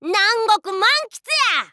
Red's voice from the official Japanese site for WarioWare: Move It!
WWMI_JP_Site_Red_Voice.wav